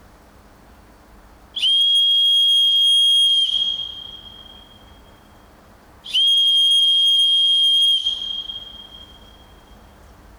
The sound is sharp, clear and penetrates well.
The sound emitted is quite high pitched and could easily be lost in the surroundings.
Sternum strap whistle